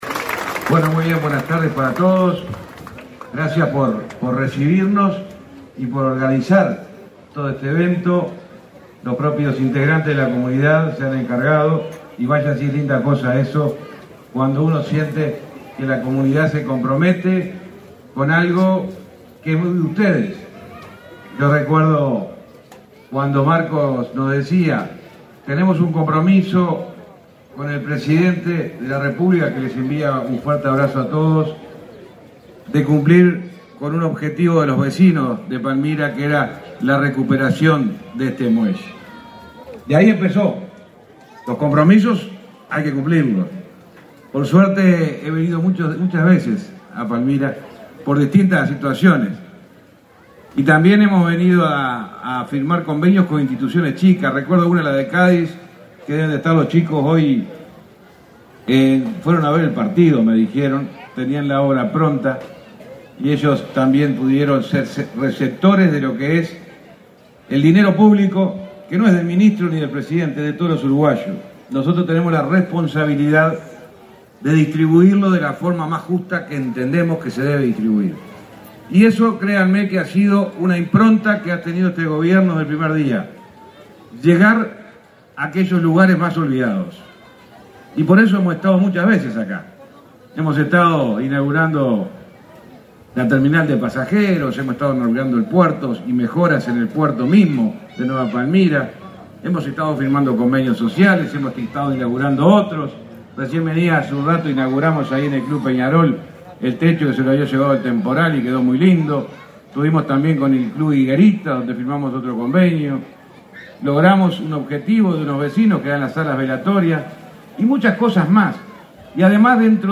Palabras del ministro de Transporte y Obras Públicas, José Luis Falero
El ministro de Transporte y Obras Públicas, José Luis Falero, participó, este 15 de noviembre, en la inauguración del muelle General, en Nueva Palmira